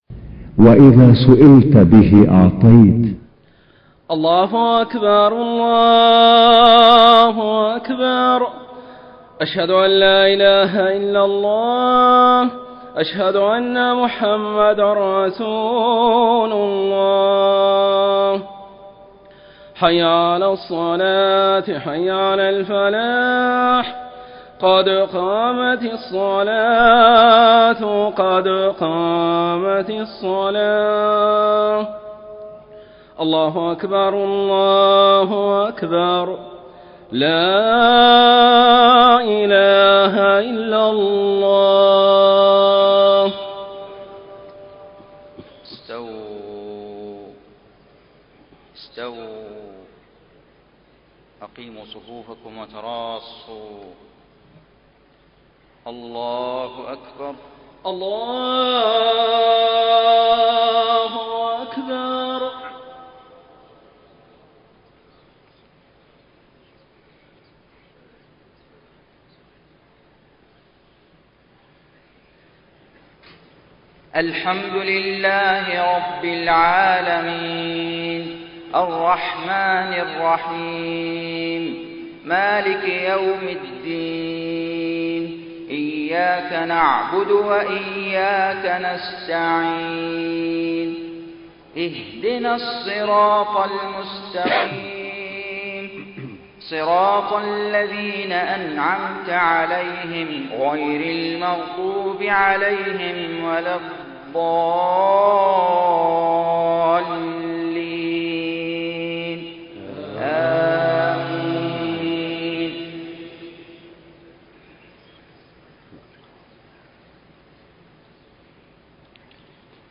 صلاة الفجر 11 ذو القعدة 1432هـ من سورة غافر 1-20 > 1432 🕋 > الفروض - تلاوات الحرمين